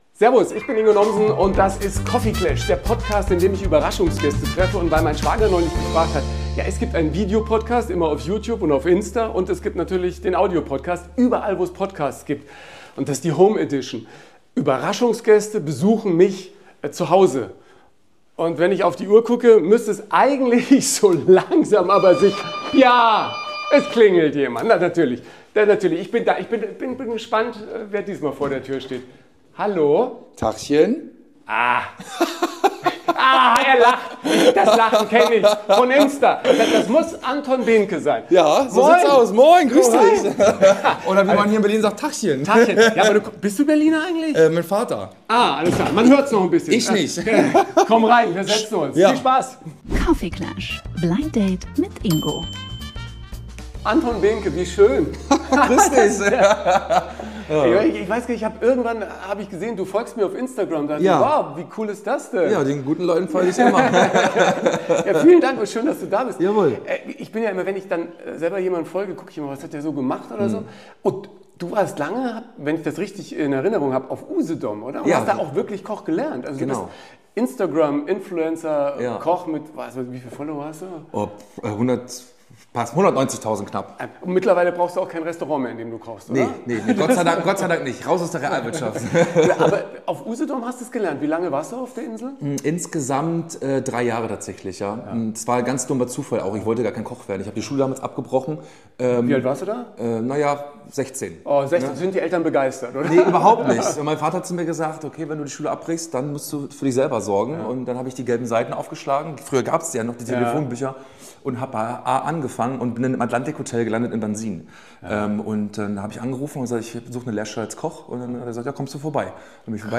Ein Gespräch über Ehrgeiz, Essen und echte Leidenschaft.